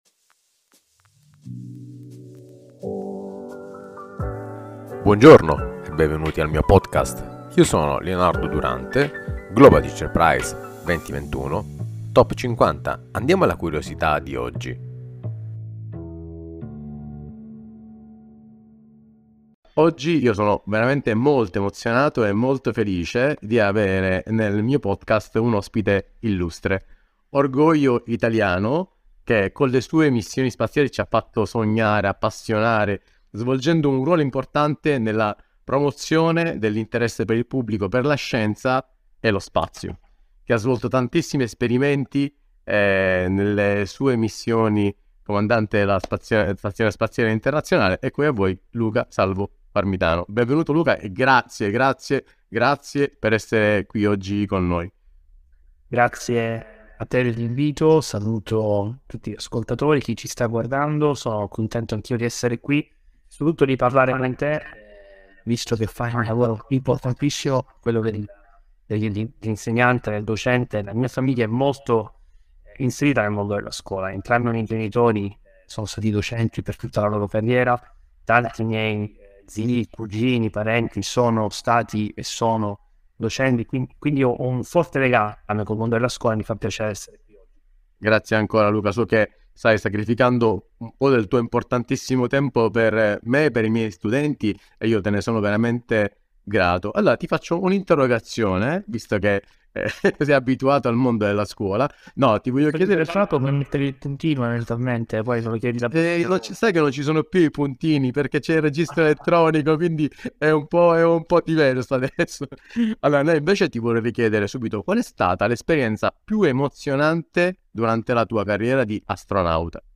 Intervista con l'astronauta Luca Parmitano!